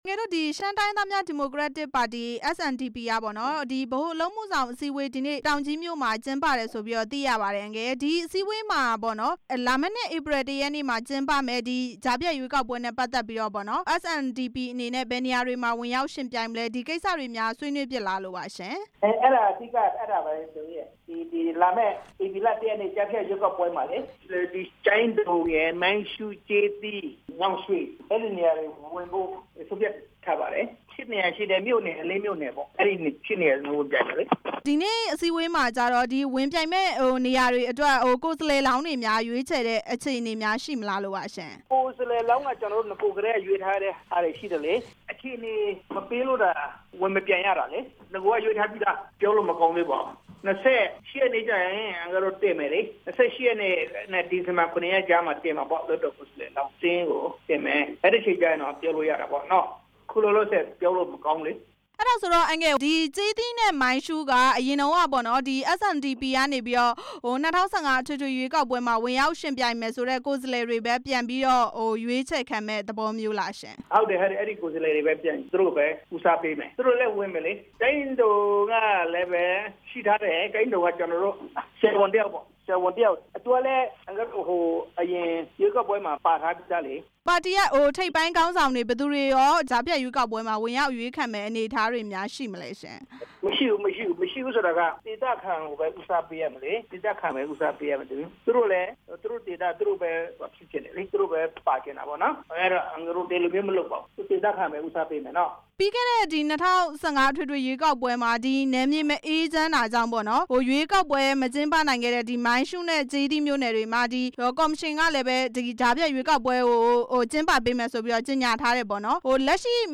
SNDP ပါတီ ကြားဖြတ်ရွေးကောက်ပွဲ ဝင်ပြိုင်မယ့်အကြောင်း မေးမြန်းချက်